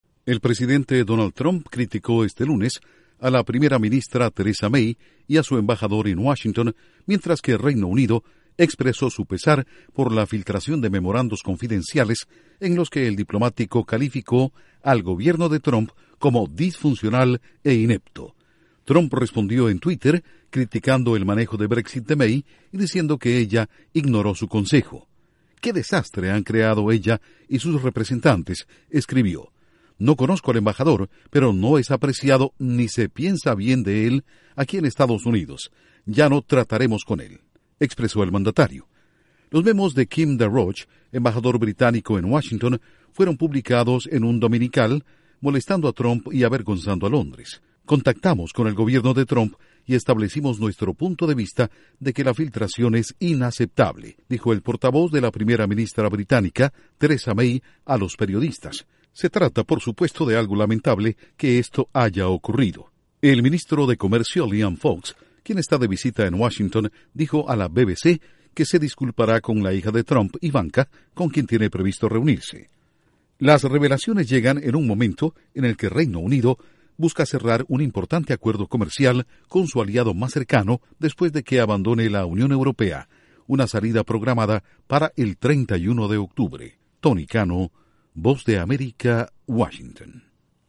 Trump arremete contra May y embajador británico que describió su gobierno como “disfuncional” e “inepto”. Informa desde la Voz de América en Washington